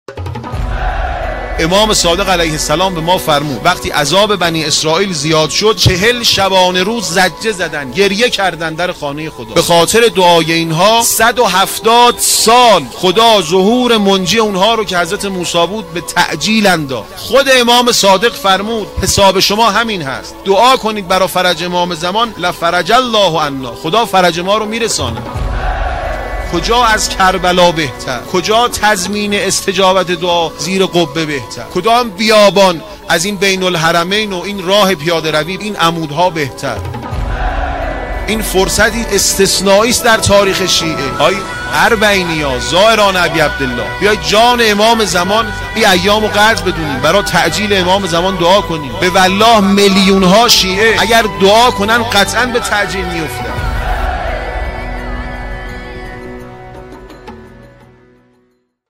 سخنرانی | دعا برای فرج امام زمان(عج) در اربعین و حرم امام حسین(ع)